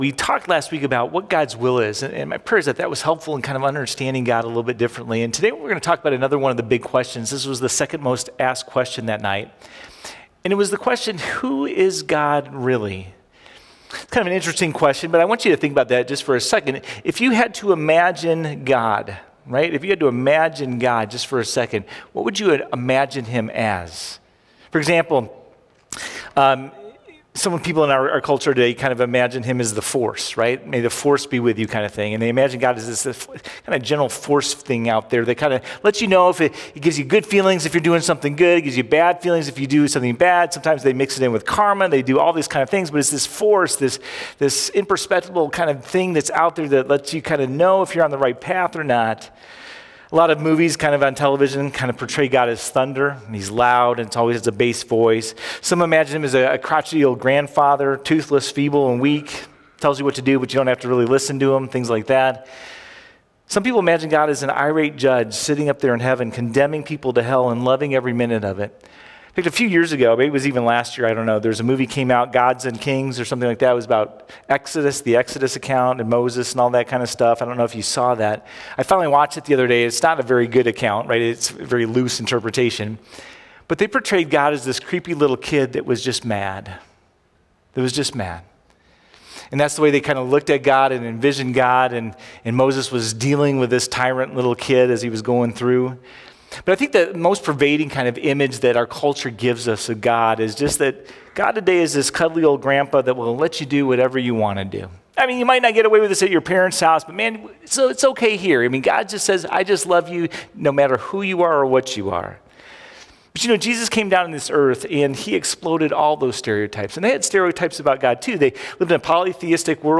05-04-Sermon.mp3